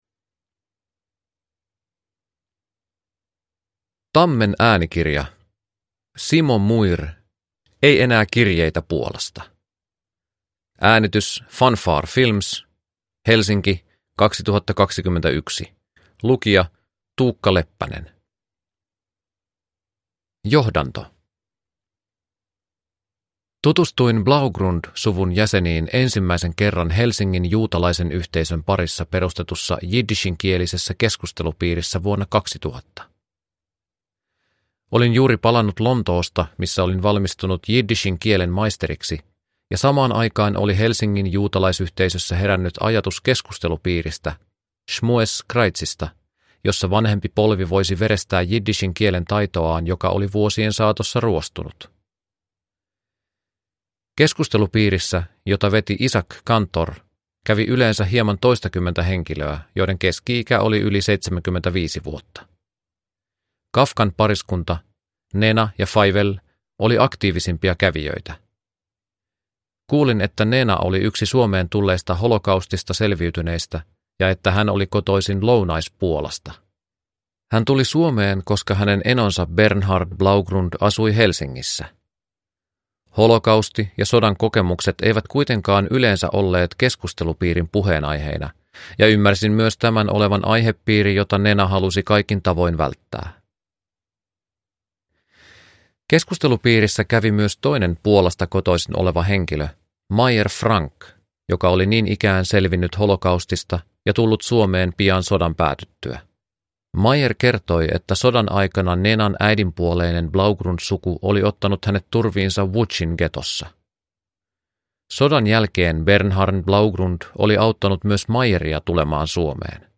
Ei enää kirjeitä Puolasta (ljudbok) av Simo Muir